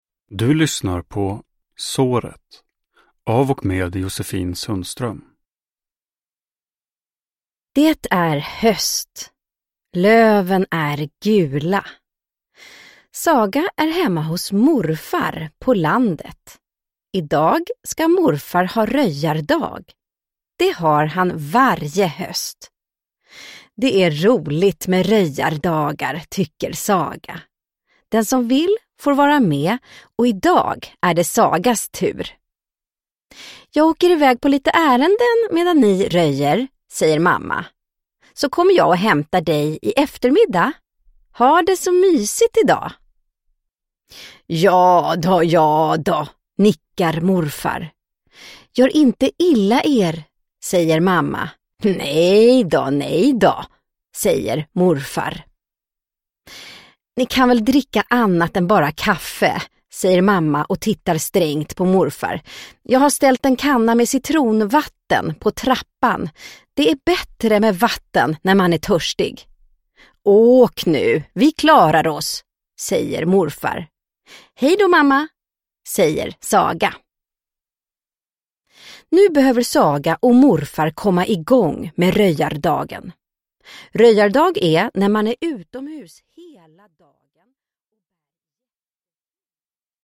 Såret - läs tillsammans – Ljudbok – Laddas ner